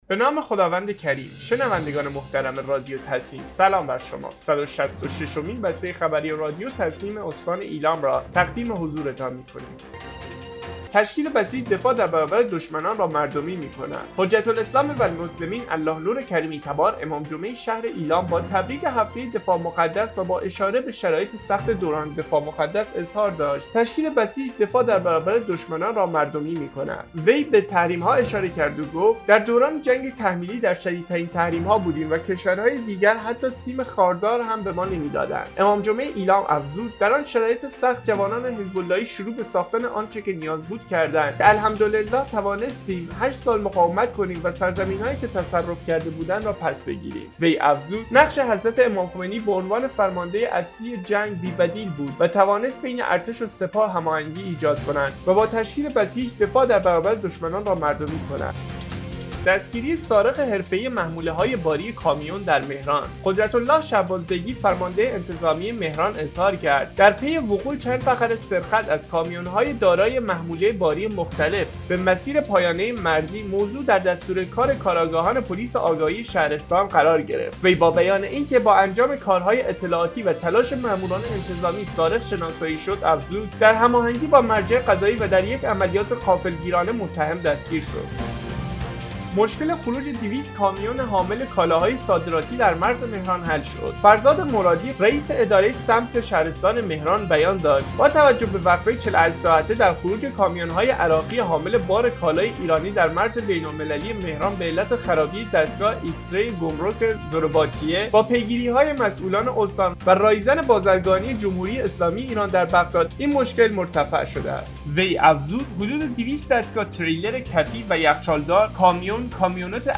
به گزارش خبرگزاری تسنیم از ایلام، صد و شصت و ششمین بسته خبری رادیو تسنیم استان ایلام باخبرهایی چون تشکیل بسیج دفاع در برابر دشمنان را مردمی کرد، ‌دستگیری سارق حرفه‌ای محموله‌های باری کامیون در«مهران» و مشکل خروج 200 کامیون حامل کالاهای صادراتی در مرز مهران حل شد، منتشر شد.